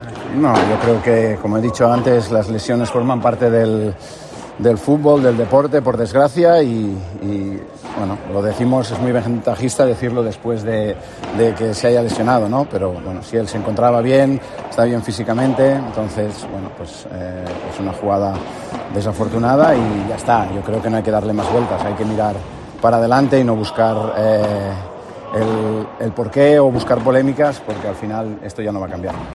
El exfutbolista del Barcelona habló con los medios de comunicación a la finalización de un acto.